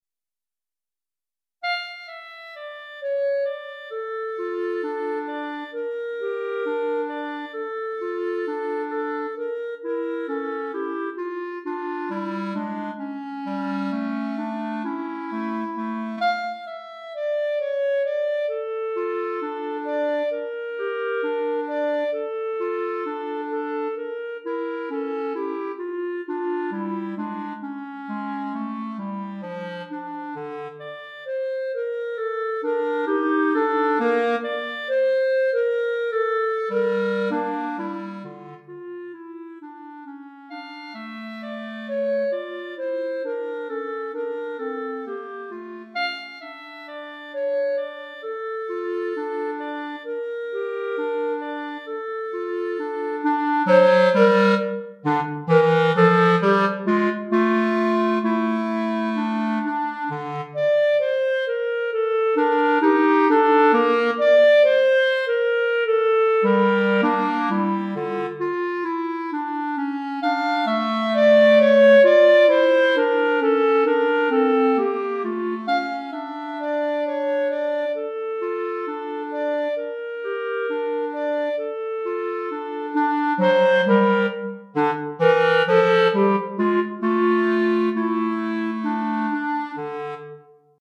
Répertoire pour Clarinette - 2 Clarinettes